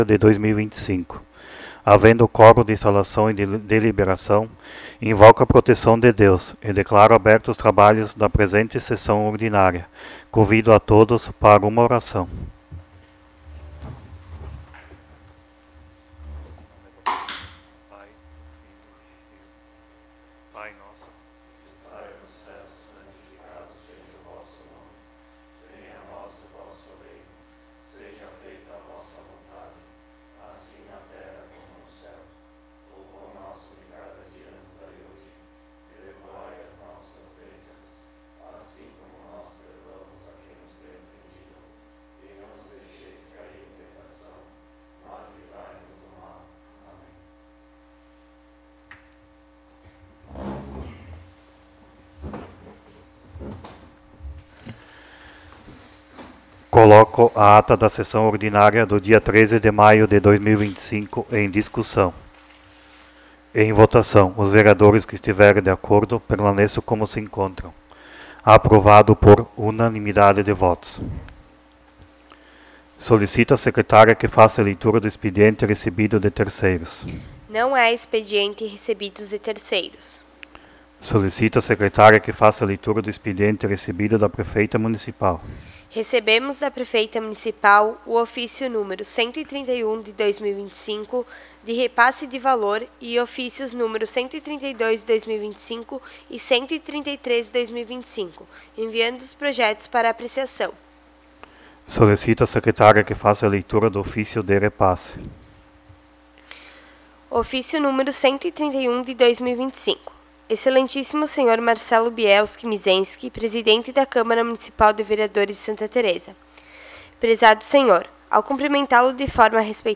08° Sessão Ordinária de 2025